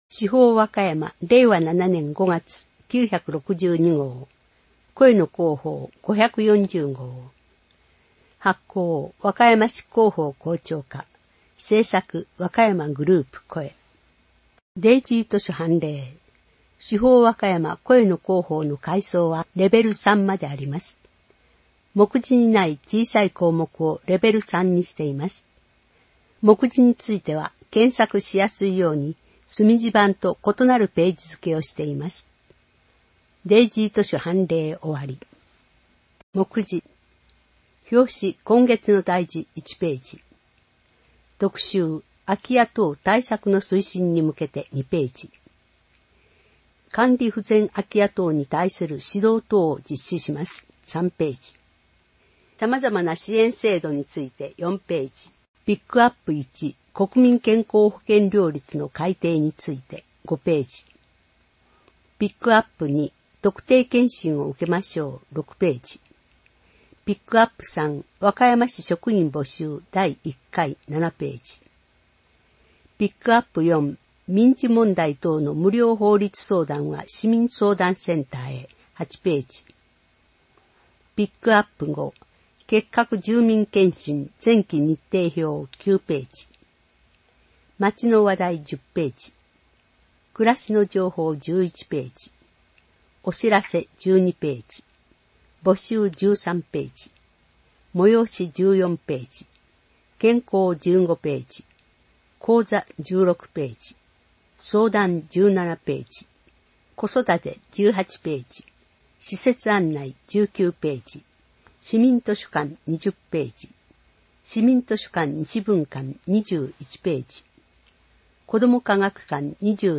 市報わかやま 令和7年5月号（声の市報）